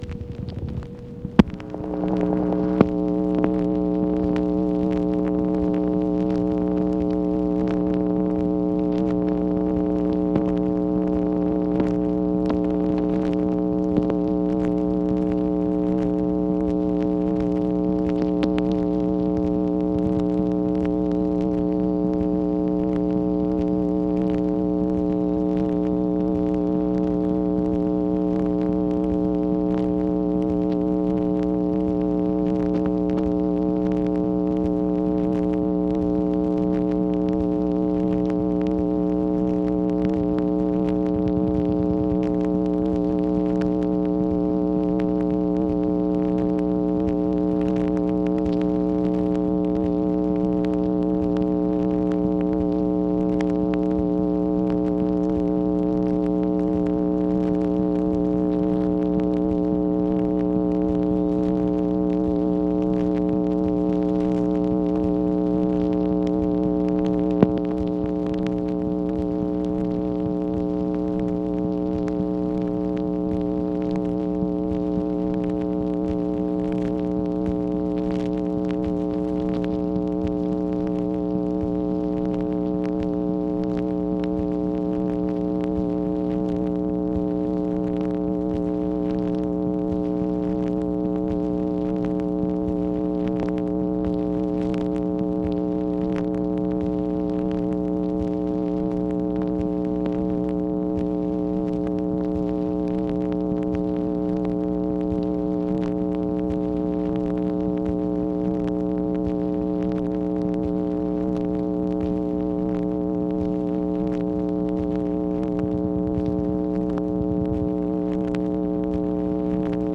MACHINE NOISE, August 26, 1965
Secret White House Tapes